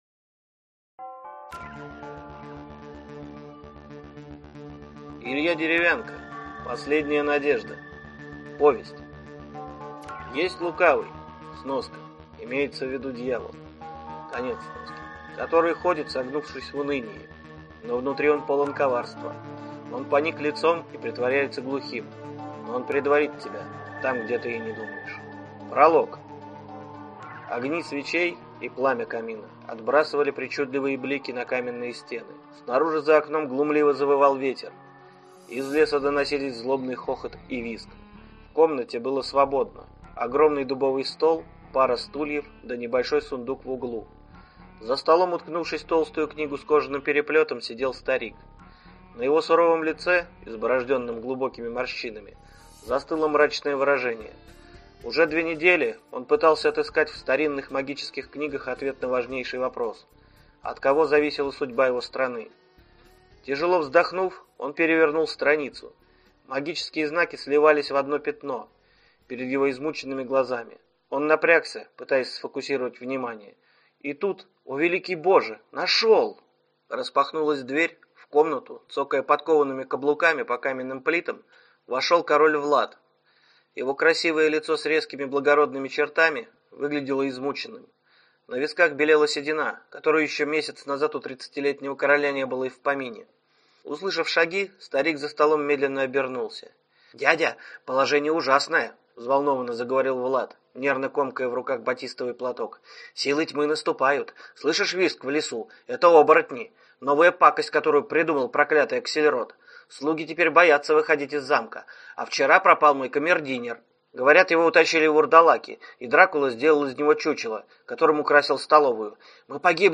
Аудиокнига Последняя надежда | Библиотека аудиокниг